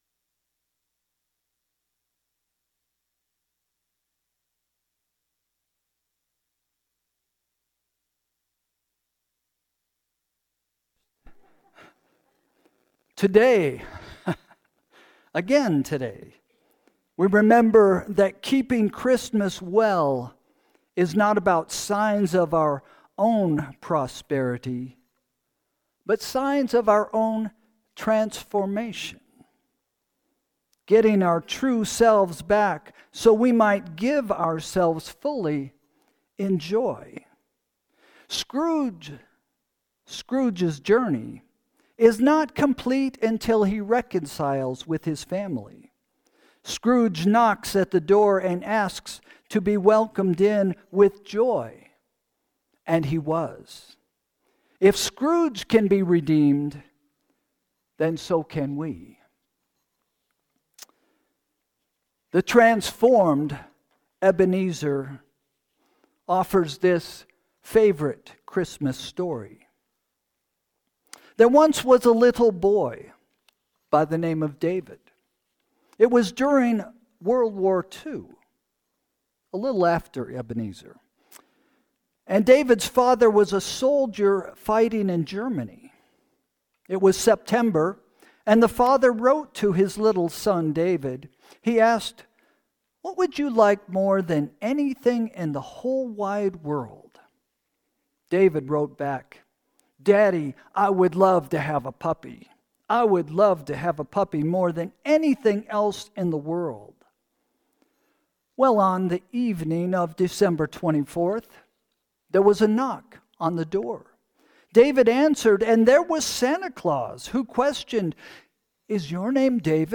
Christmas Eve Meditation
It was part of our annual service of carols to welcome the birth of the Messiah.